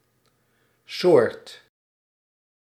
Ääntäminen
IPA: /so:ɾt/